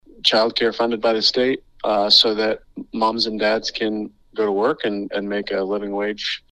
CLICK HERE to listen to commentary from Democrat Representative Forrest Bennett.